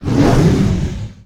ogg / general / combat / creatures / dragon / he / hurt1.ogg
hurt1.ogg